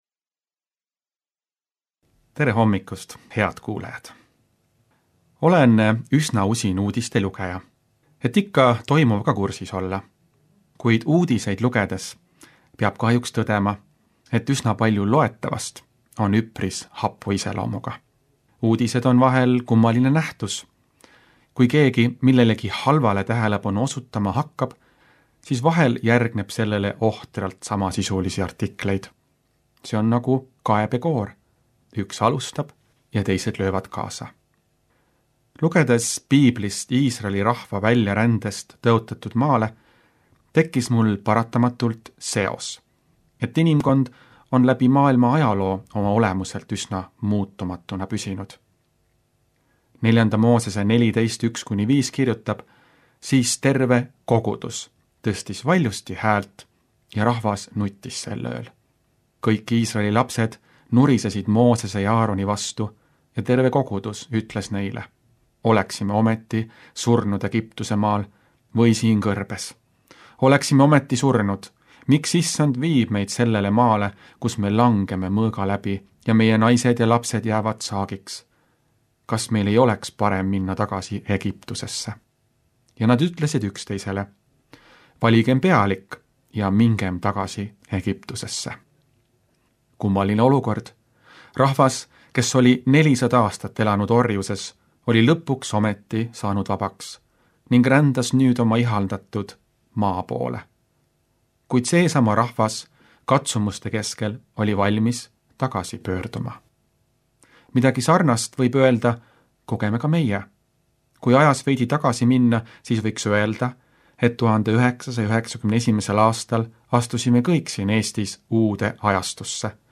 hommikupalvus Pereraadios 03.11.2025